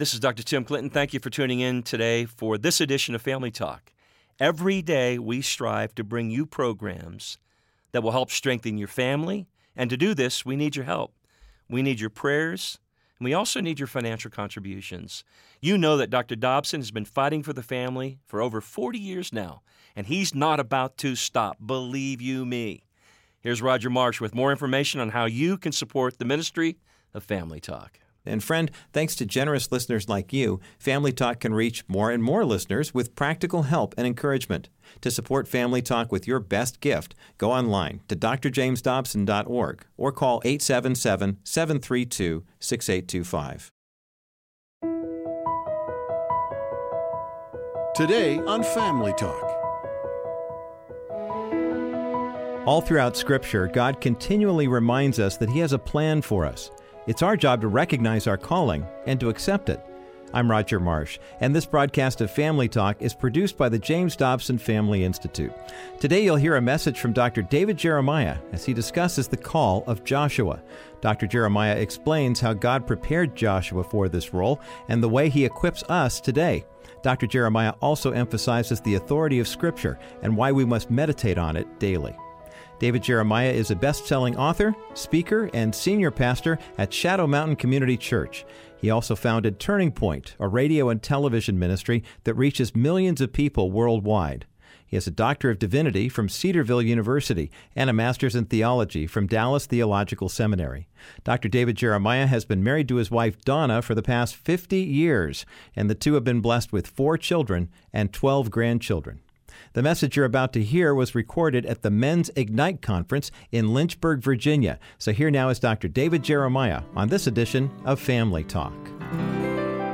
Dr. David Jeremiah preaches on the call of Joshua to an audience of men gathered at the Mens Ignite Conference in Lynchburg, Virginia this past spring. He explains how God prepares us to fulfill our callings, and emphasizes the need to read, memorize and meditate on the Word of God.